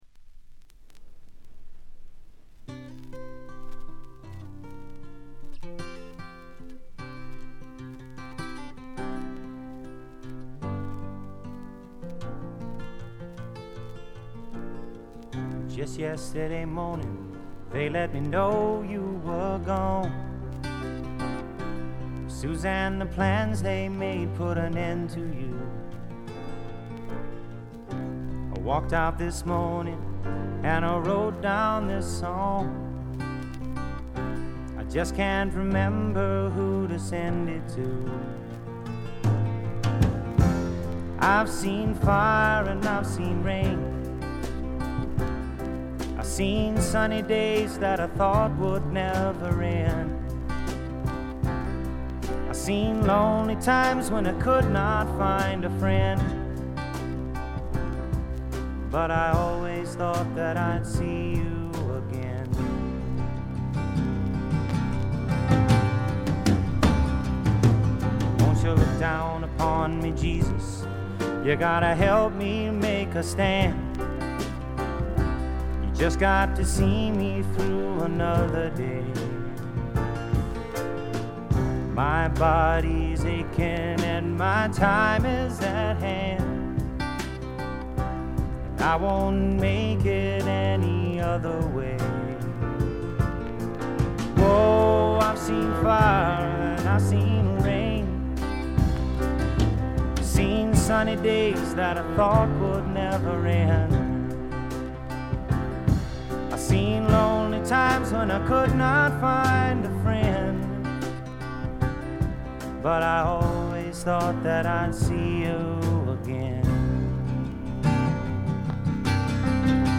バックグラウンドノイズ、ところどころでチリプチ。
試聴曲は現品からの取り込み音源です。
Recorded at Sunset Sound, December '69